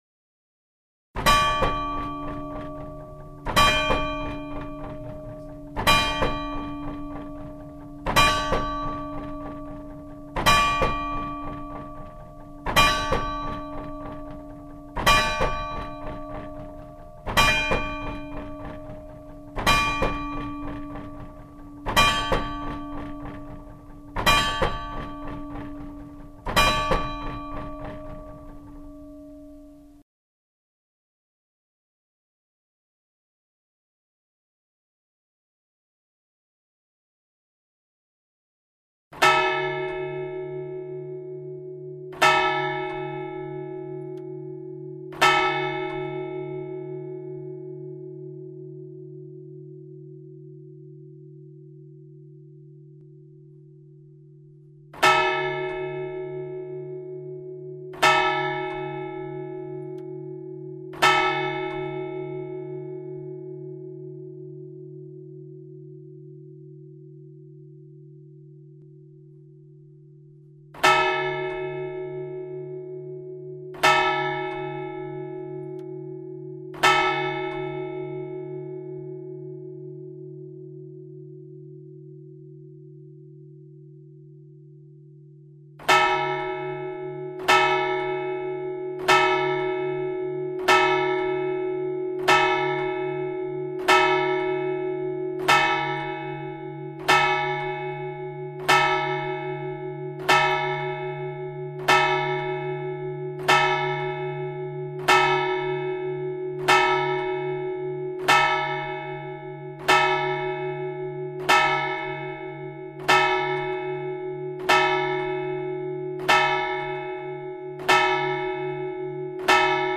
Du coup, Bodet a programmé une simulation de volée par marteau. C'est mécanique et nettement plus terne.
L'heure est sonnée sur la petite cloche, l'angélus et la simulation de volée sur la grosse cloche.
Du coup, les harmoniques de la cloche sont très profondément affectées.
Cela donne une sonorité non pas fausse, mais un peu étrange ; et franchement pas désagréable pour autant. En contrepartie, la durée de vie est extrêmement raccourcie : le son est très bref et il est mat.
Analyse /// Hum : 146 Hz - Prime : 331 Hz - Tierce : 370 Hz - Quint : 452 Hz - Nominal : 630 Hz - Superquint : 908 Hz - Oct Nom : 1314 Hz.
Note au piano : Mi, mais franchement difficile à déterminer.
Vous pouvez écouter l'heure (midi), un angelus et une simulation de mise en volée ci-dessous.